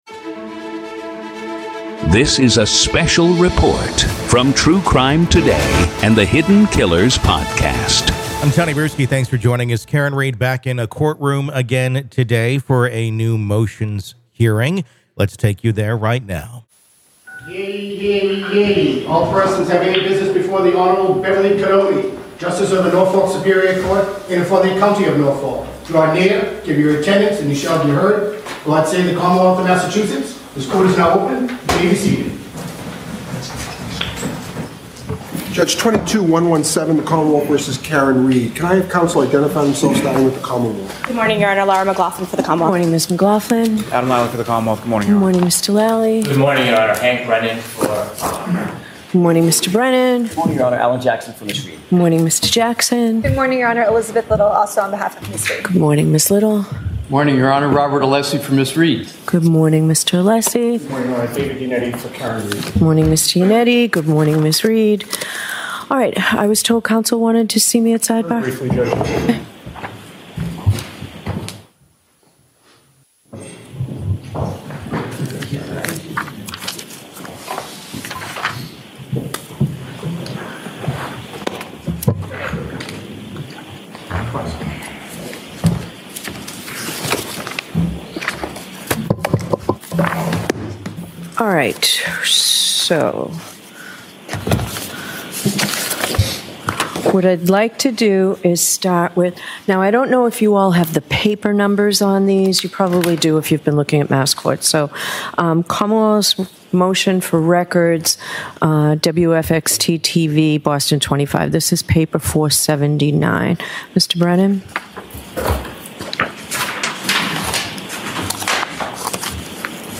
RAW COURT AUDIO: Judge Halts Karen Read Pretrial Hearing After ‘Grave Concern’ Over New Information PART 1